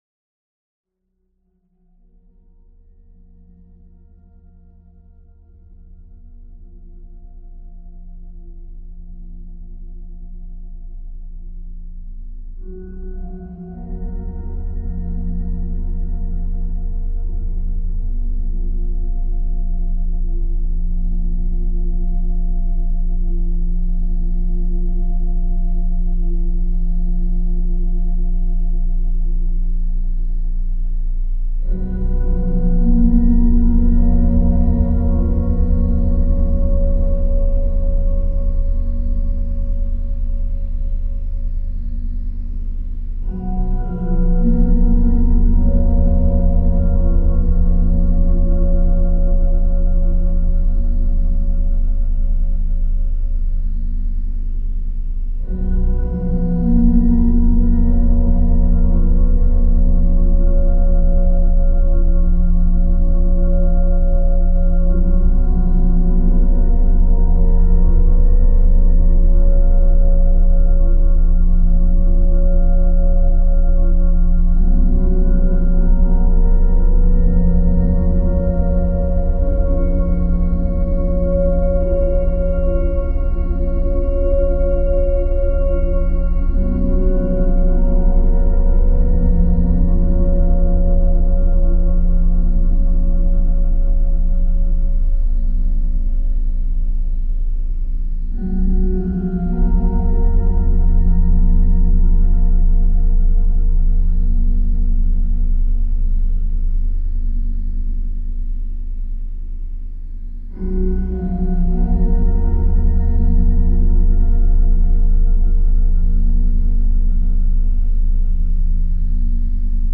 File under: Ambient / Drone Music